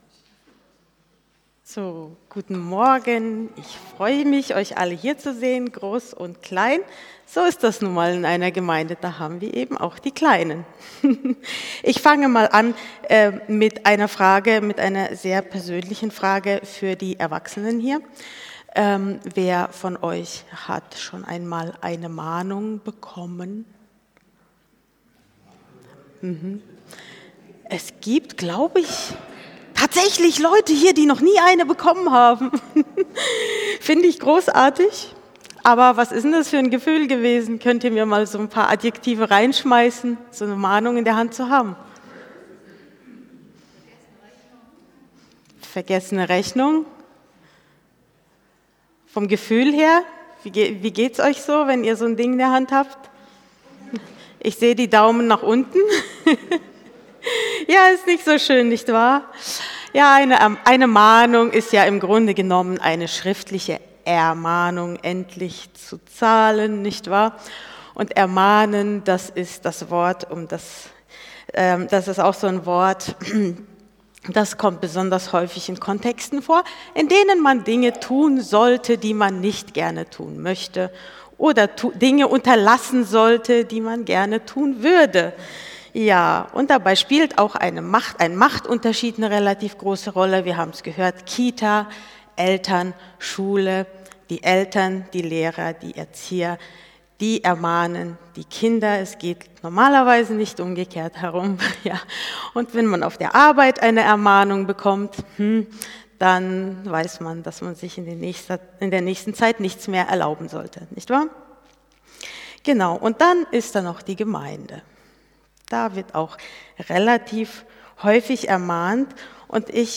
Predigt vom 08.03.2026